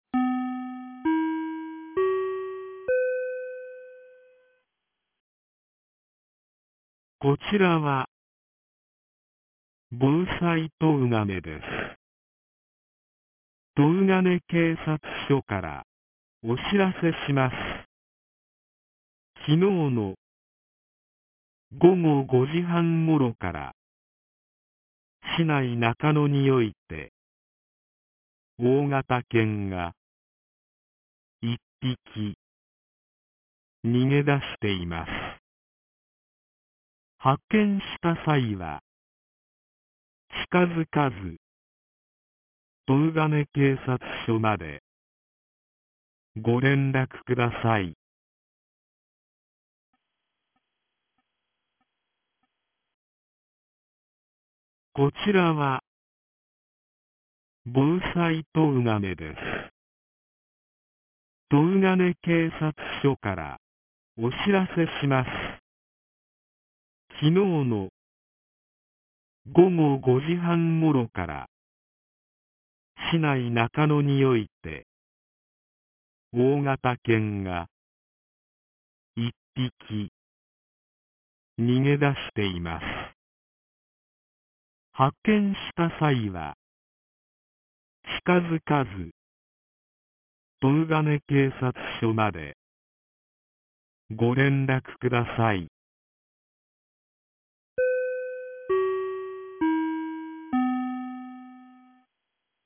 2025年04月14日 11時58分に、東金市より防災行政無線の放送を行いました。